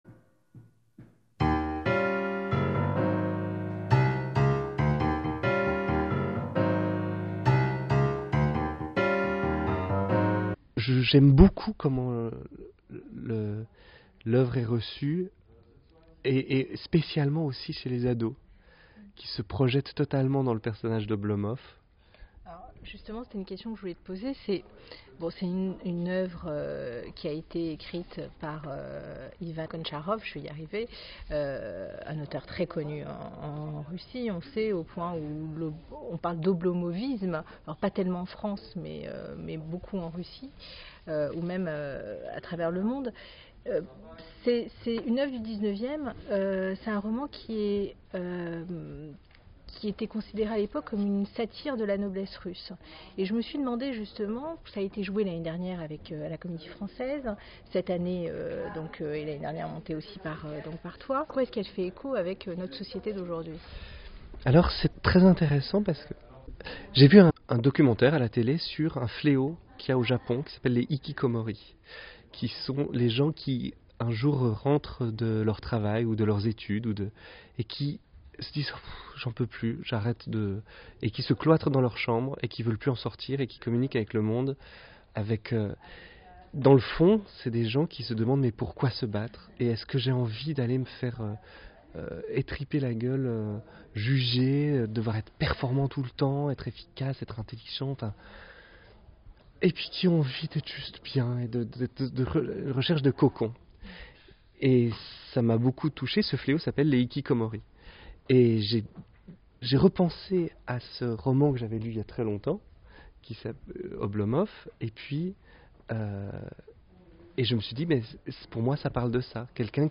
Générique de l'interview